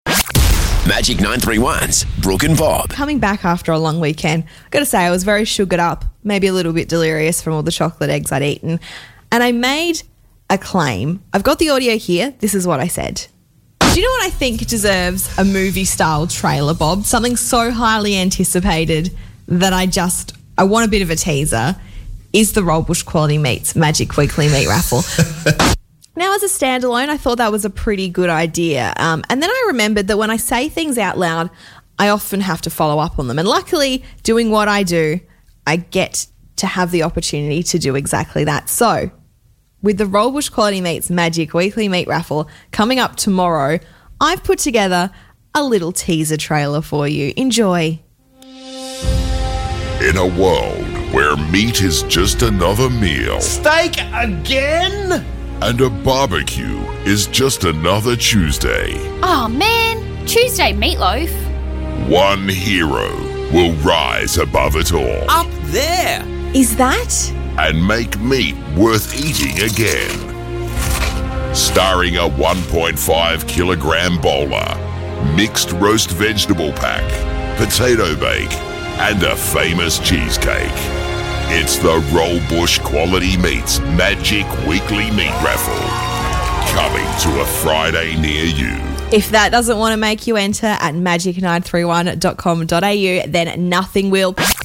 Some events need a Hollywood movie style trailer.